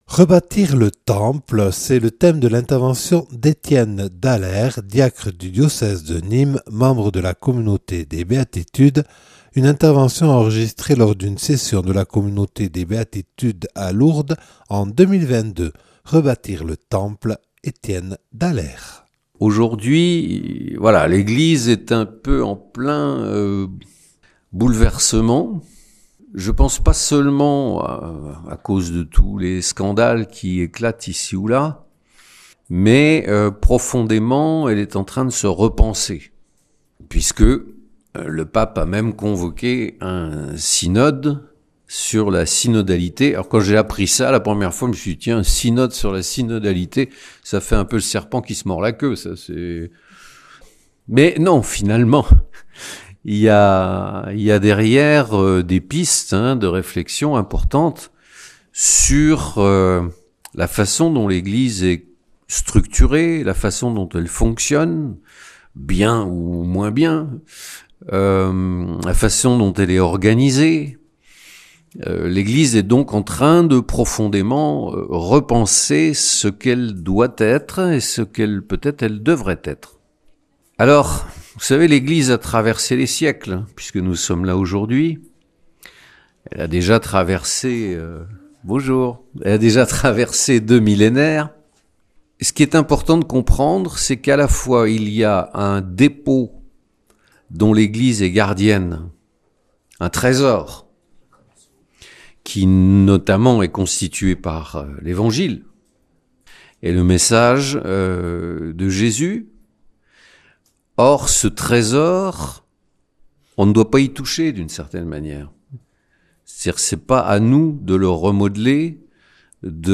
(Enregistré en 2022 à Lourdes lors d’une session des Béatitudes).